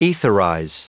Prononciation du mot etherize en anglais (fichier audio)
Prononciation du mot : etherize